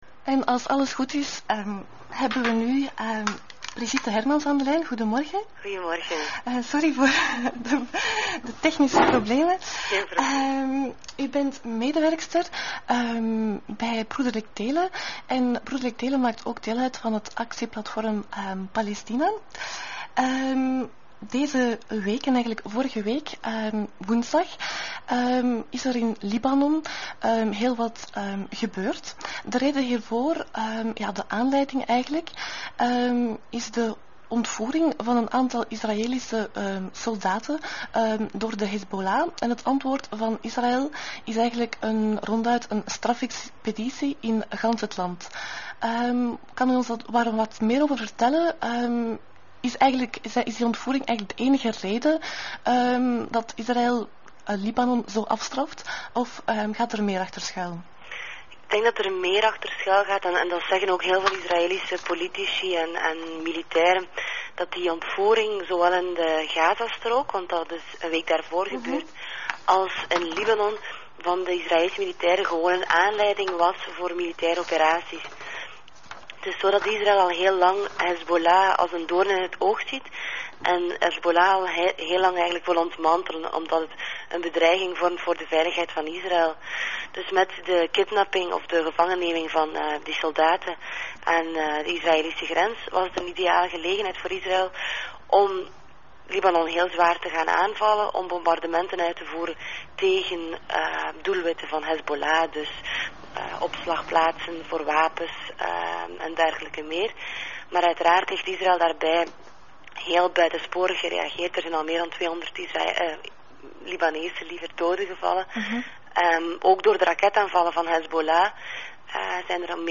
Een interview